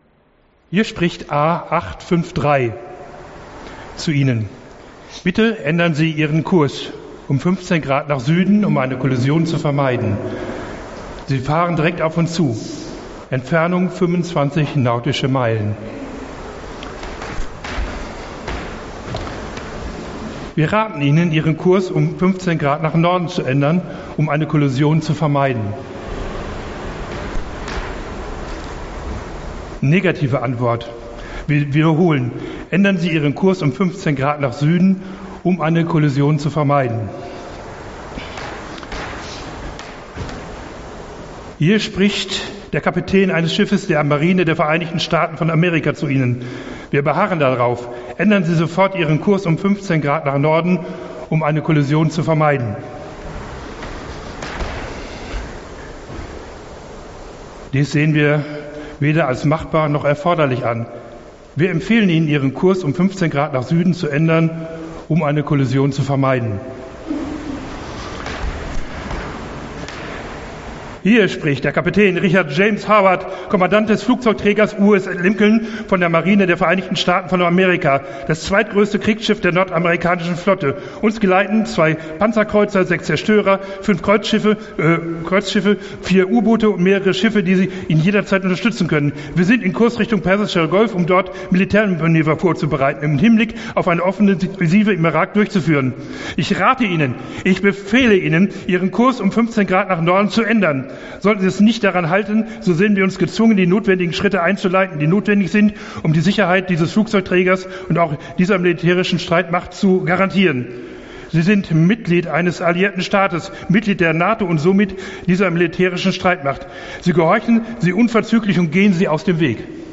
Genre: Predigt.